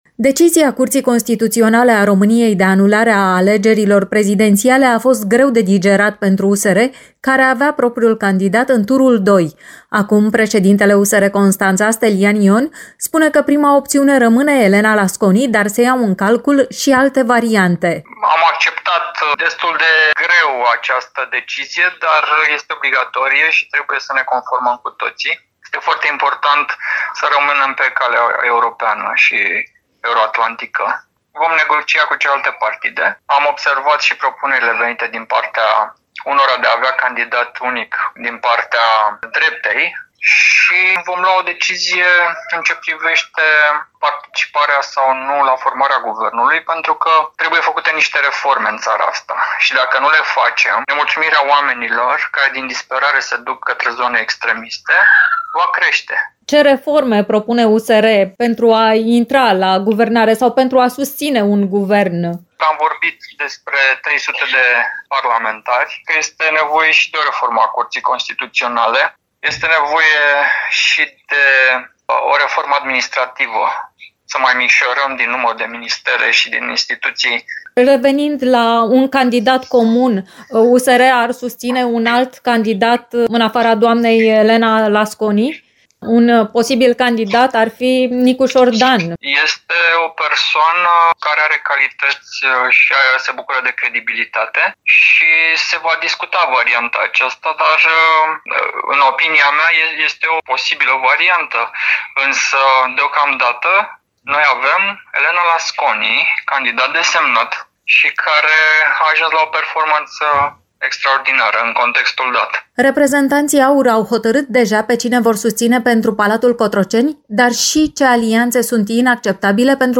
Ce candidați ar putea susține partidele parlamentare și ce alianțe se conturează pentru formarea noului Guvern, sunt întrebările la care am încercat să obținem răspuns de la politicieni din Dobrogea.